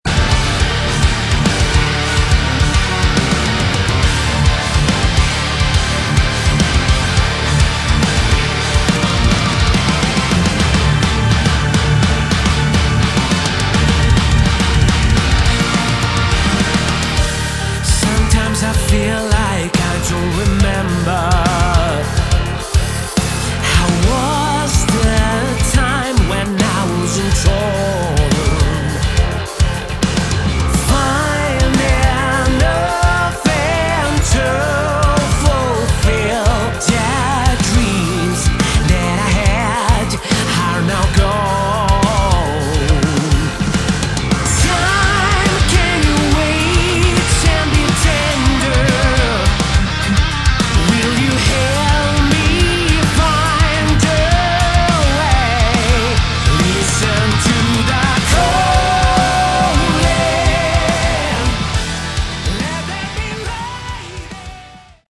Category: Melodic Metal / Prog Metal
vocals
guitars
bass
drums
keyboards